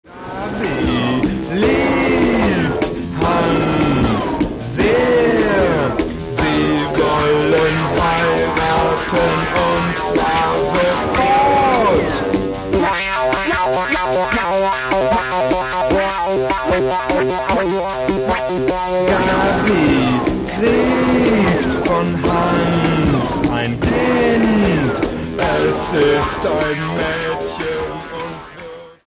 Stilbildend und vorbildlich für Analog und Lo-Tec-Fans.